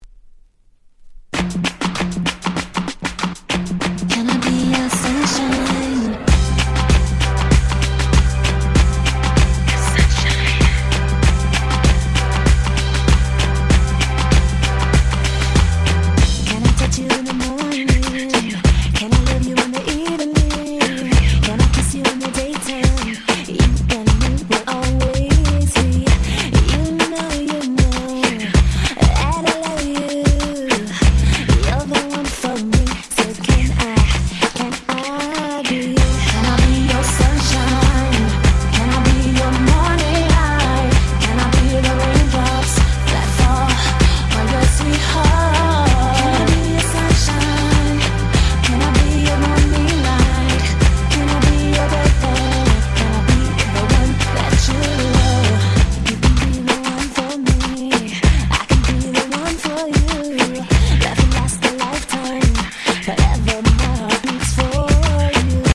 03' Nice R&B !!